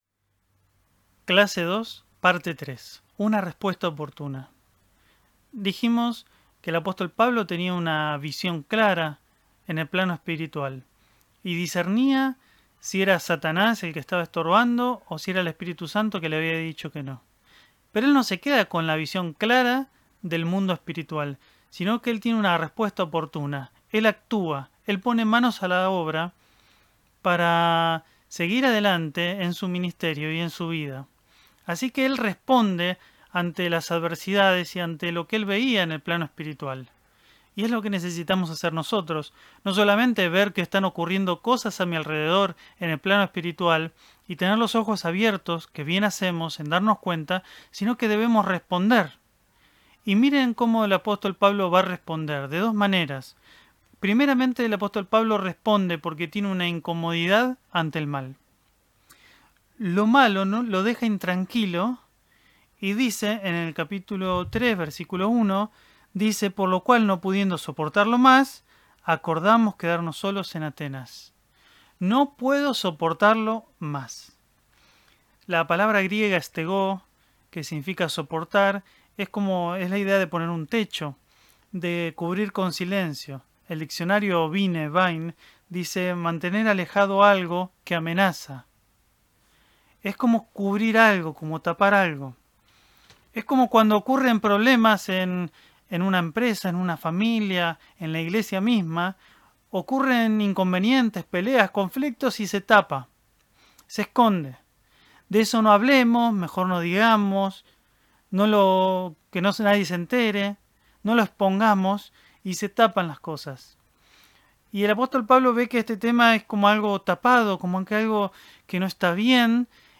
En esta segunda clase del estudio de la carta a los Tesalonicenses realizado por la Escuela Bíblica de Ibew.